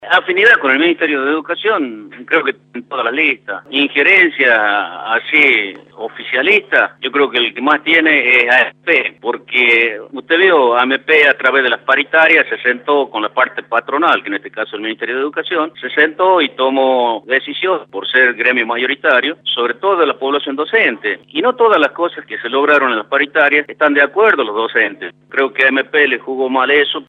En diálogo con Radio La Red agregó: “Un gremio está para defender las condiciones dignas de trabajo, salarios y que no se descuente el impuestos a las ganancias; eso no defendieron y sí injirieron para investigar a docentes”.